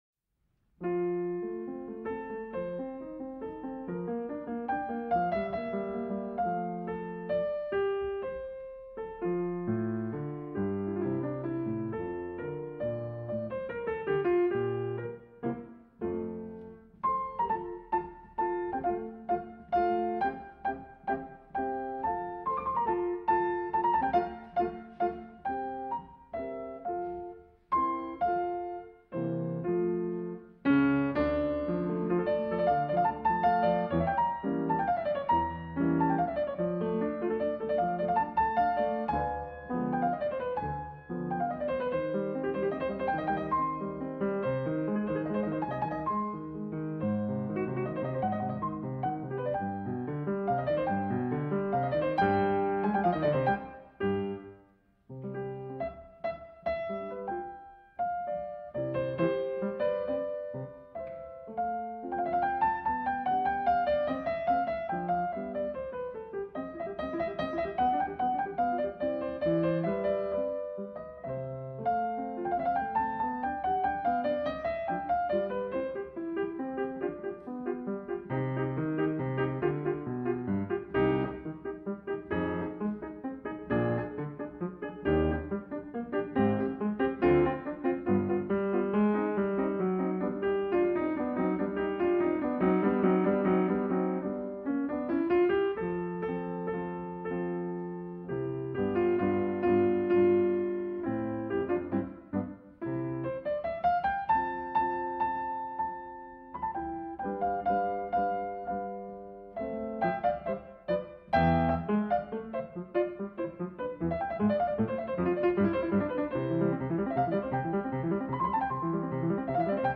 Mozart – Piano ambiental para relajación y concentración